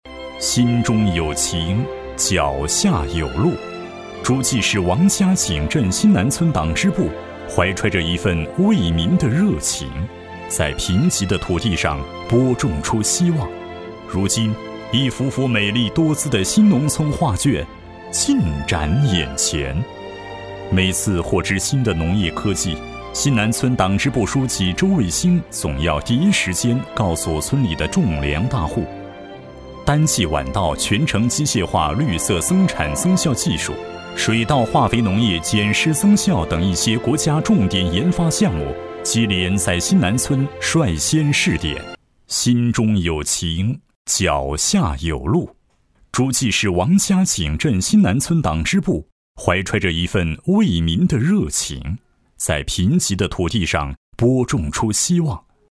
稳重磁性 企业专题,人物专题,医疗专题,学校专题,产品解说,警示教育,规划总结配音
磁性男中音，大气稳重、浑厚洪亮。